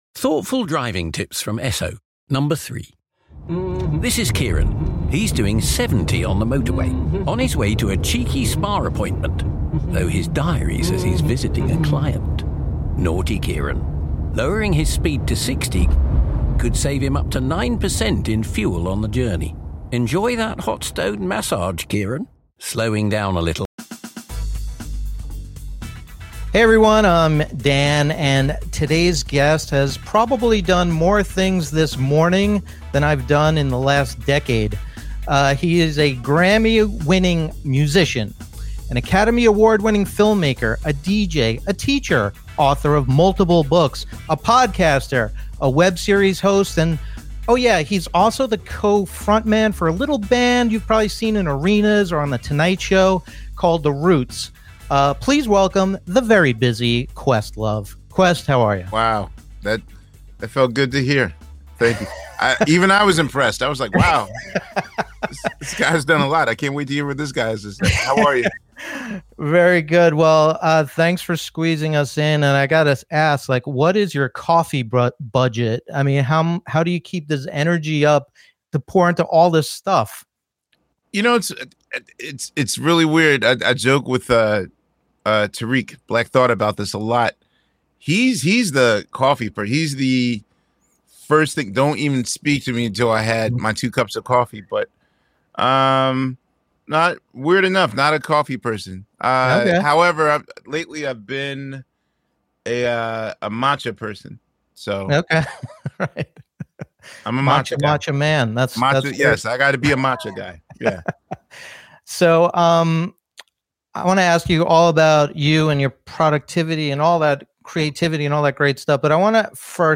In this conversation, the very busy Questlove talks about being crazy productive without going crazy, re-capturing your creative juices and the surprising song that is guaranteed to get a party going.